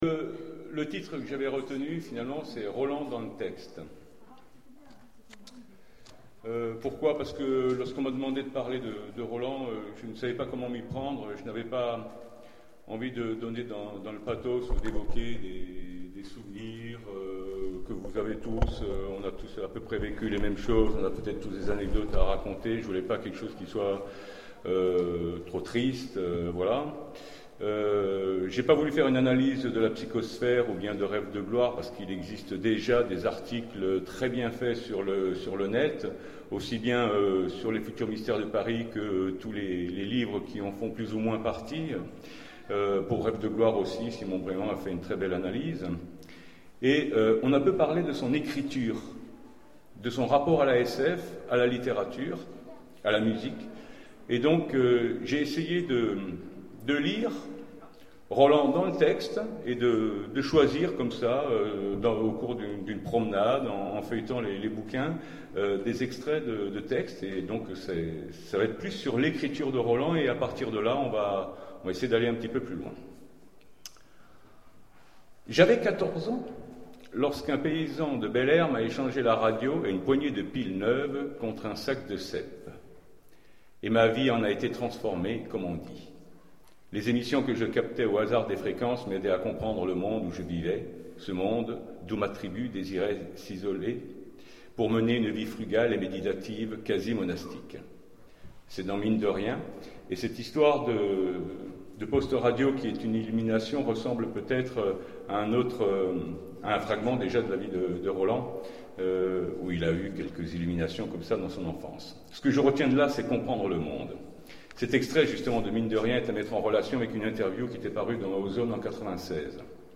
Convention SF d'Aubenas - RCW